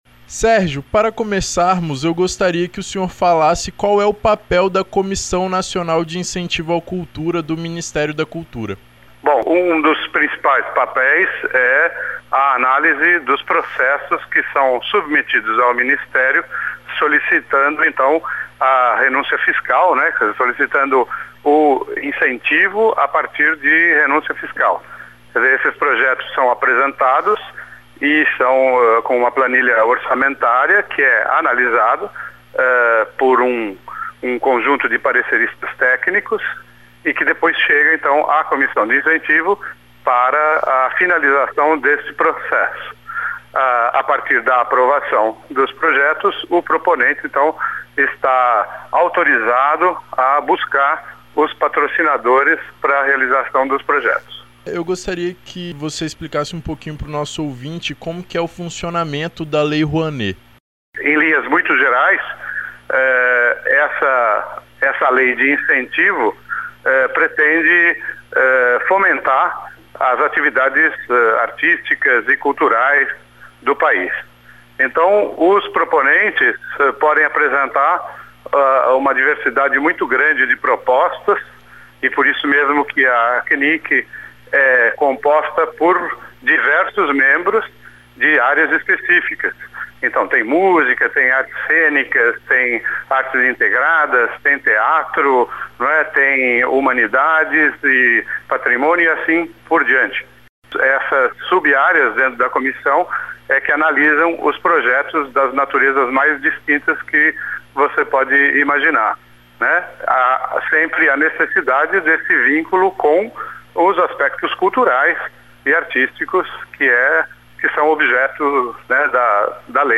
O Revista Universitária conversou com